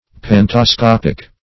Search Result for " pantascopic" : The Collaborative International Dictionary of English v.0.48: Pantascopic \Pan`ta*scop"ic\, a. Viewing all; taking a view of the whole.